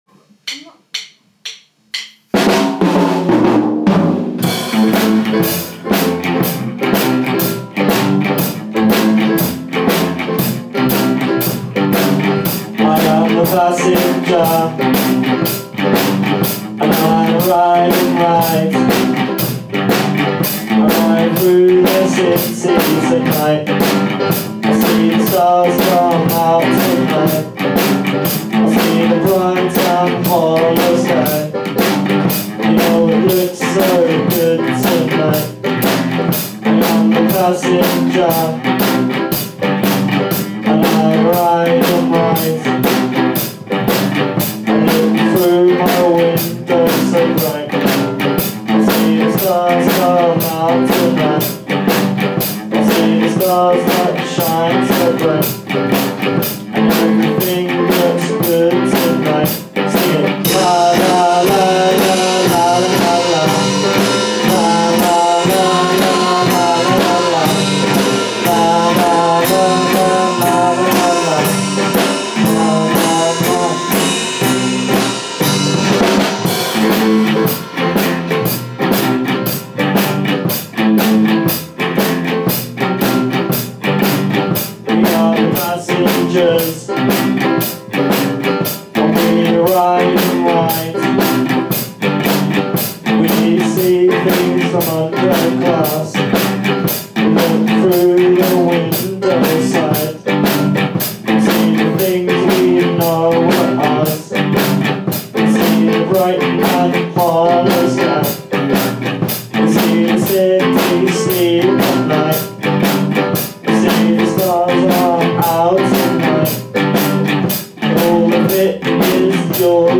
Guitar
Drums
Bass
Piano